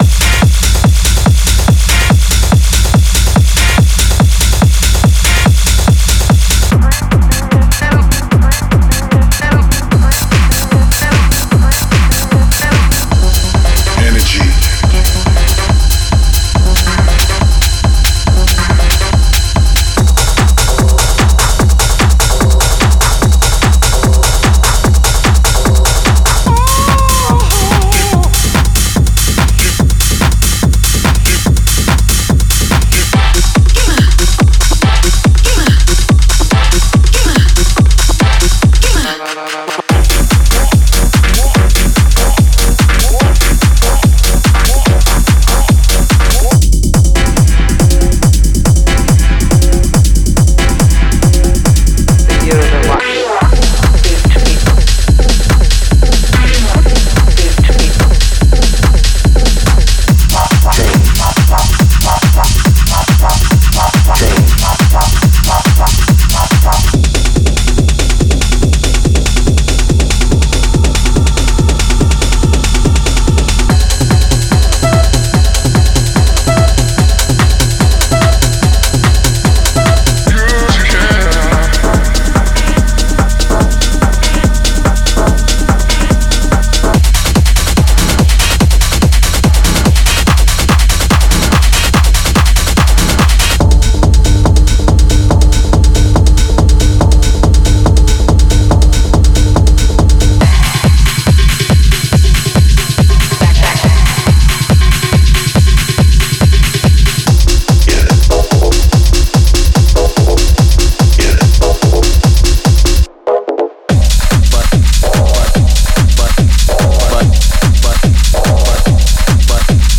Genre:Techno
デモサウンドはコチラ↓
223 Beat Loops